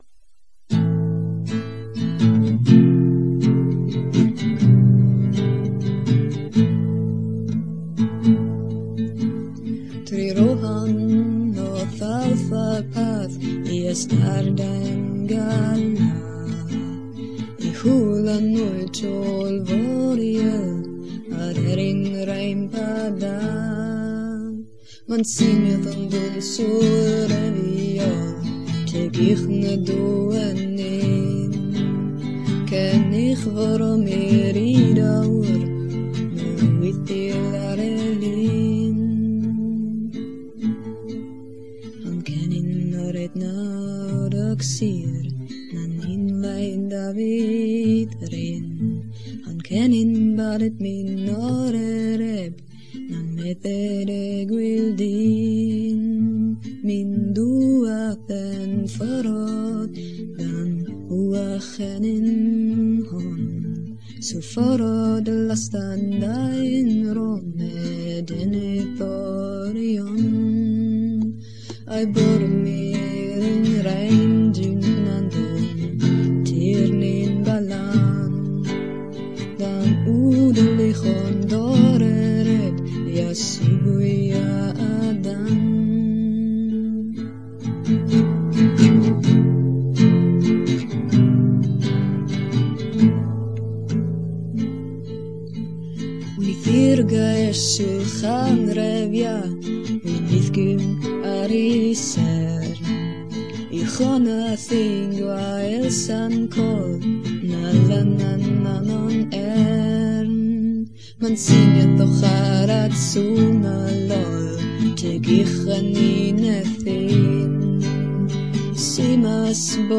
Edhellen 'lirnen - Sung Elvish